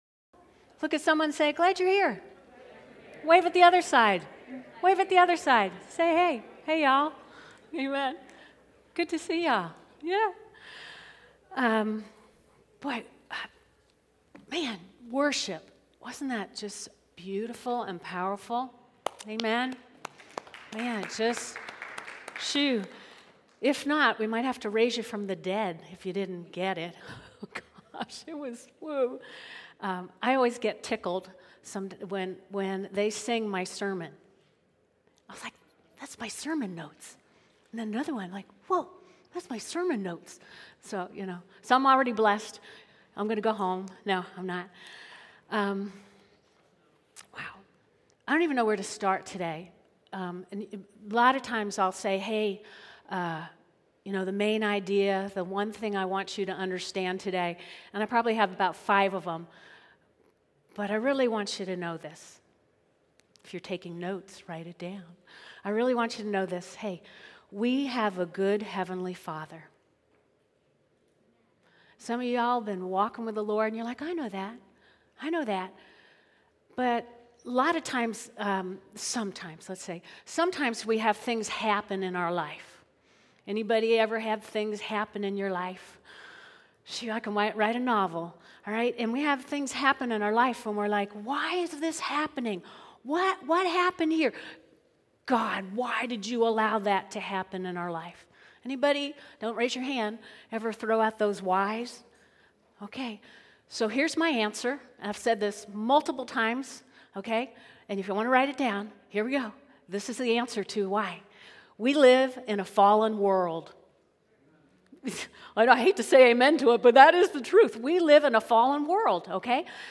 Service Sunday AM Service Tweet Summary Our Heavenly Father is a good good God.